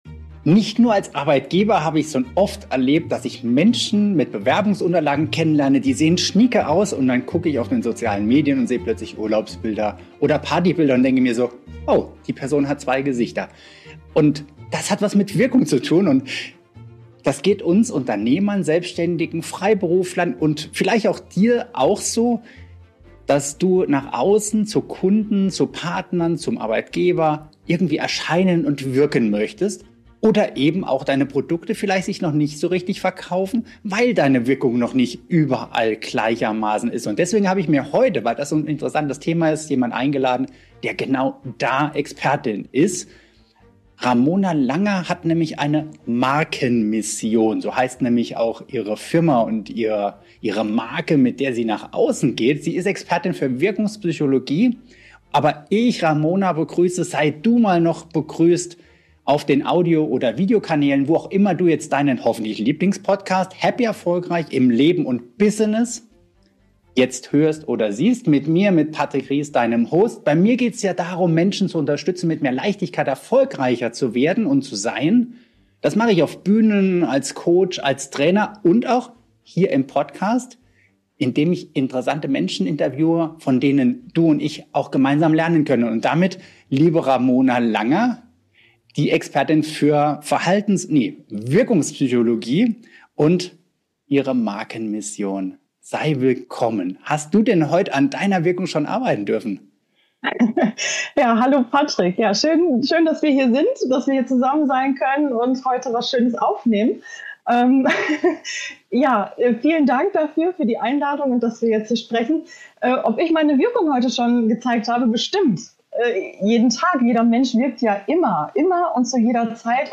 Du erfährst in diesem Interview: Warum Business-Erfolg kein Zufall, sondern das Ergebnis gezielter Wirkung ist Welche emotionalen Trigger Menschen ins Handeln bringen Wie du mit klarer Positionierung und Nutzerführung Content entwickelst, der verkauft Diese Episode ist für dich, wenn du deine Selbstvermarktung schärfen und mit deiner Außendarstellung echte Relevanz schaffen willst.